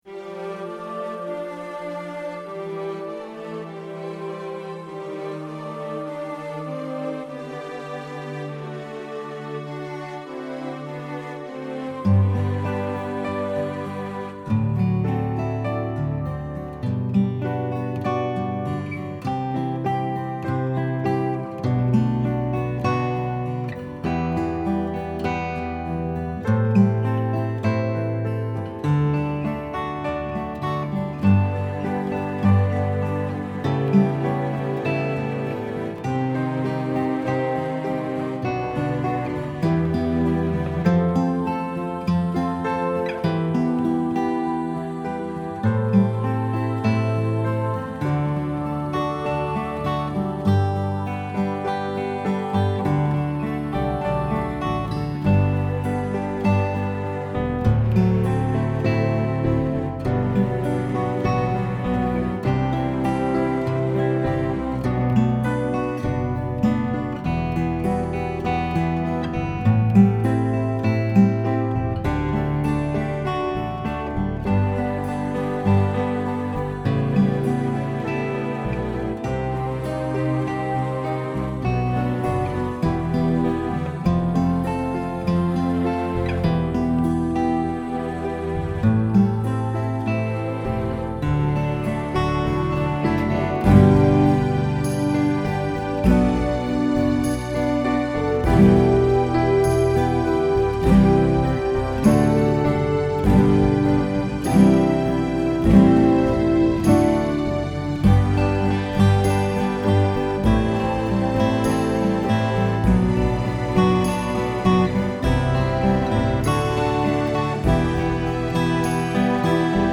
I have the guitar louder so I can hear it clearly to check my editing.
rainbow-through-my-tears-guitar-mix.mp3